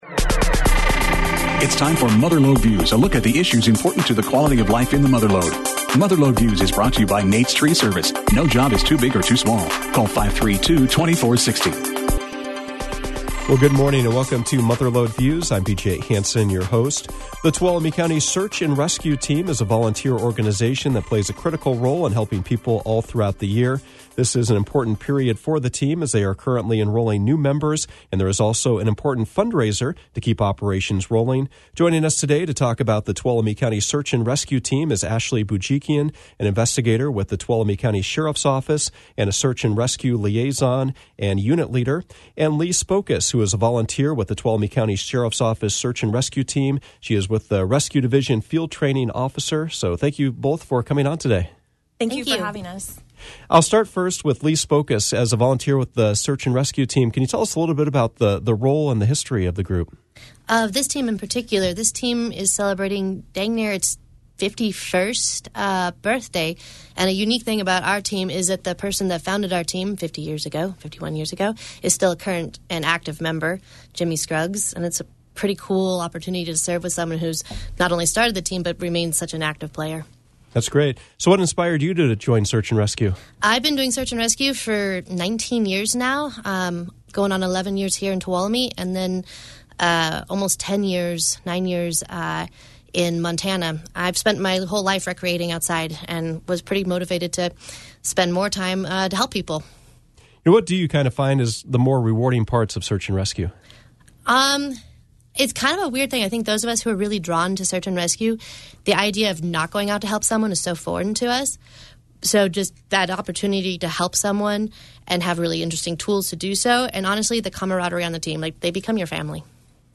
Mother Lode Views featured a conversation with a pair of leaders with the Tuolumne County Sheriff’s Office Search and Rescue Team.